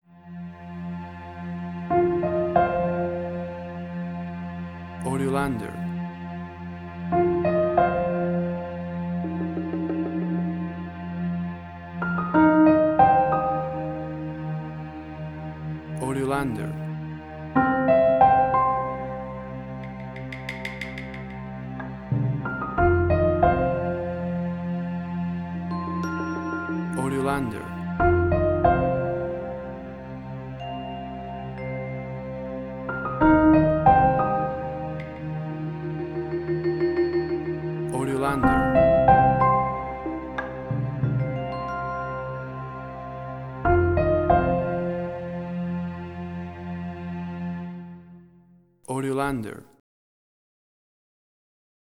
emotional music
Tempo (BPM): 92